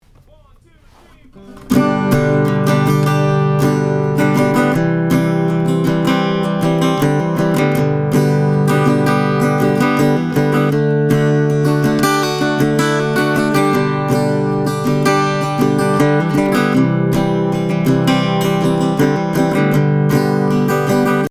I turned off the EQ and normalized the tracks.
-And I tracked a project where the guy brought in a 1960's Gibson parlor acoustic guitar:
View attachment vintage Gibson parlor.mp3